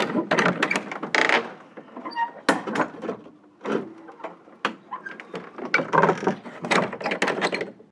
Abrir o cerrar una puerta de madera antigua
puerta
Sonidos: Hogar